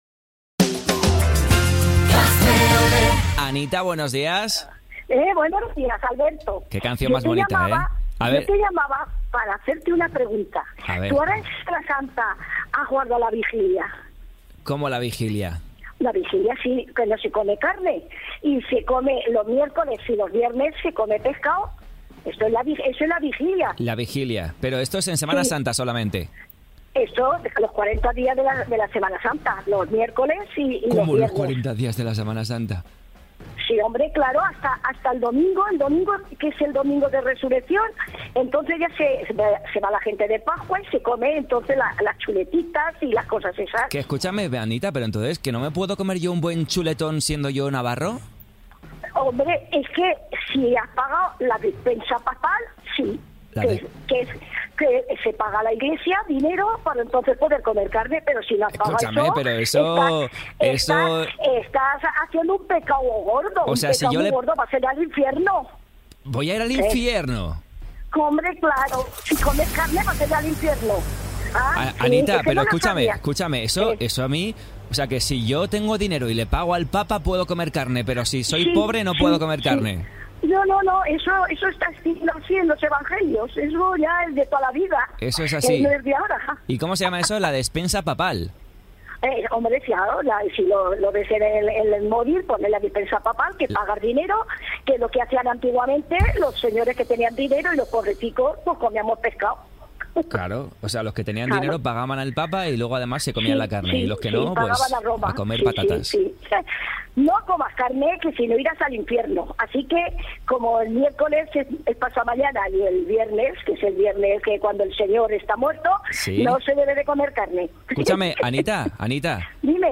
El programa despertador de Radiolé, de lunes a viernes de 07.00 a 12.00 h